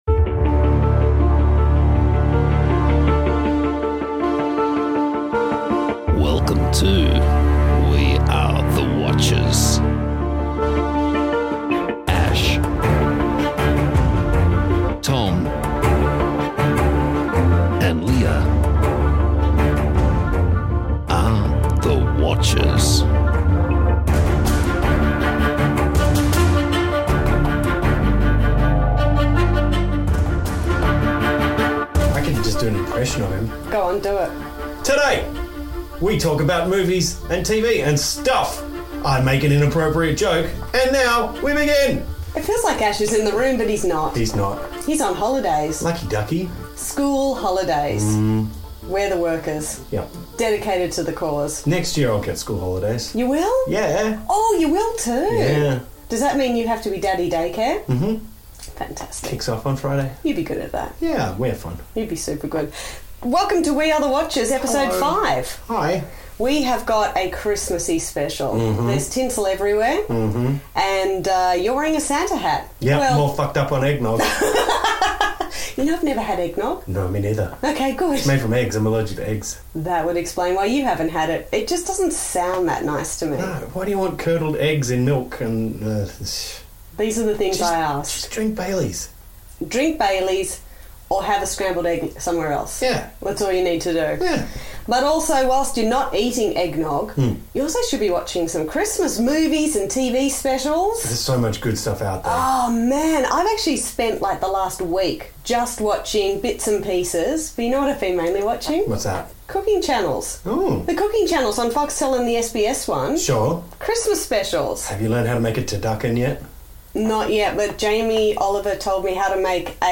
We didn't record in our usual swanky studio, so please forgive the substandard sound quality. It's still rich with TV and movie recommendations for the festive season.